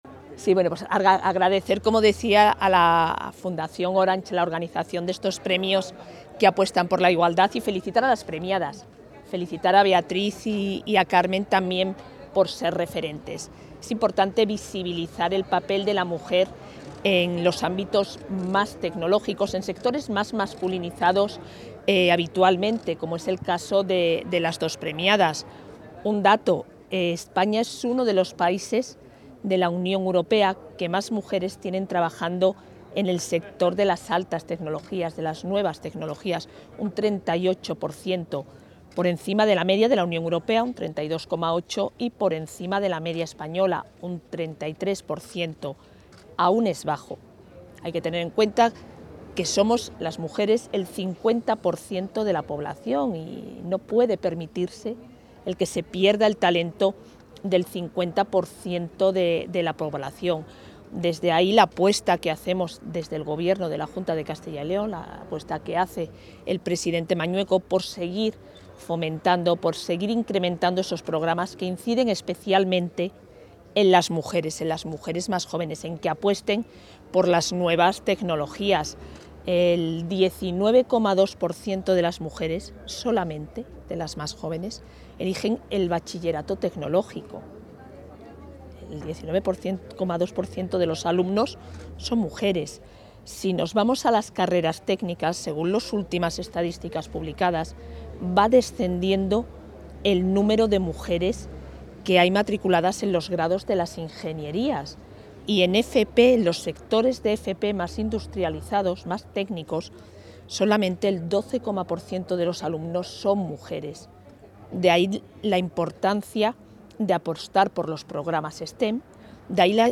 La vicepresidenta de la Junta de Castilla y León y consejera de Familia e Igualdad de Oportunidades, Isabel Blanco, ha sido la encargada de...
Intervención de la vicepresidenta.
La vicepresidenta y consejera de Familia e Igualdad de Oportunidades ha clausurado la X edición del Premio Mujer y Tecnología-Fundación Orange, donde ha recordado que el plazo para inscribirse en la presente edición finaliza el 15 de octubre y también ha reiterado la apuesta del Gobierno autonómico por la 'FP STEM', iniciativa mediante la cual se reforzará que las mujeres cursen estas disciplinas dentro de la Formación Profesional.